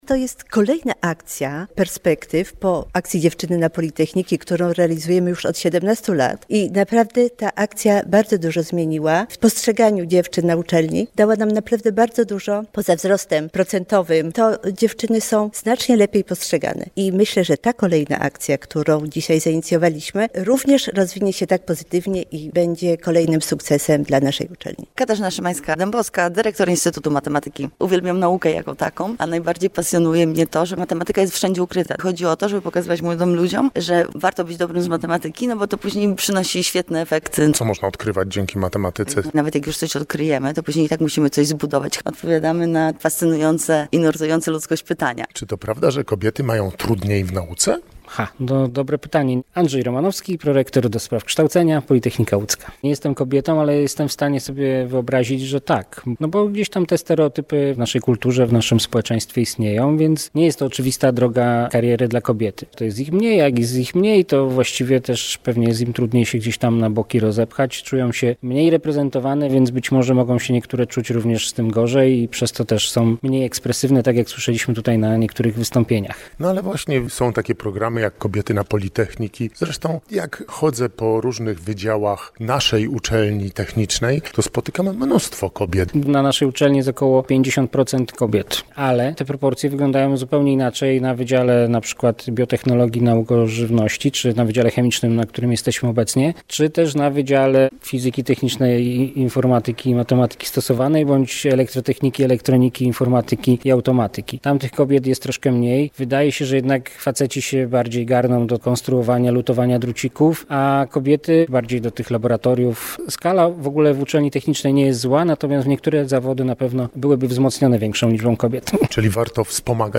Na Politechnice Łódzkiej odbywała się dzisiaj (wtorek, 13 lutego) minikonferencja poświęcona kobietom, które realizują swoją karierę zawodową w naukach ścisłych i technologii.